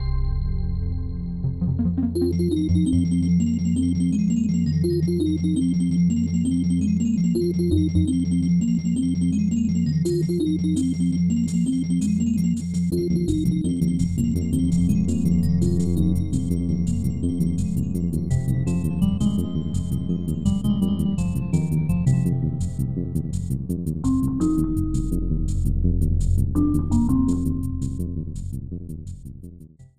Snowy theme